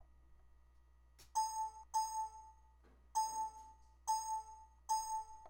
016_House_WindowsError
computer pc windows sound effect free sound royalty free Memes